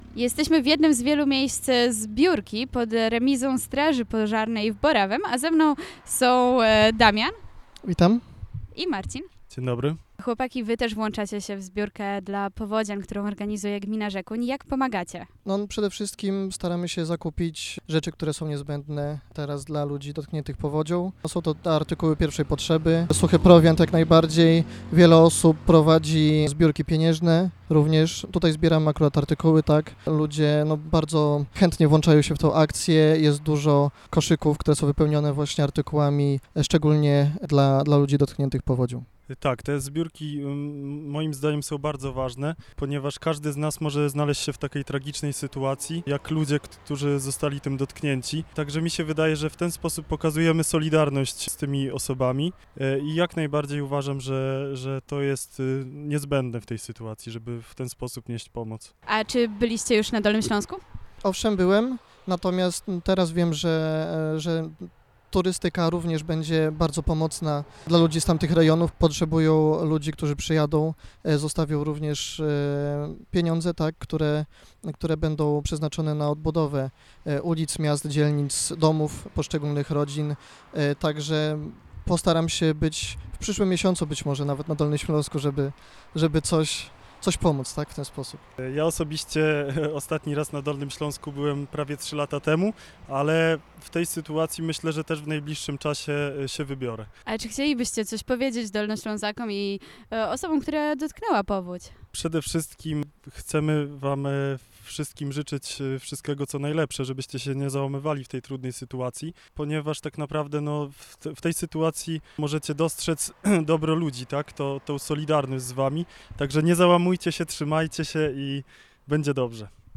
02_gmina-Rzekun_mieszkancy-ktorzy-wlaczyli-sie-w-akcje_NW.mp3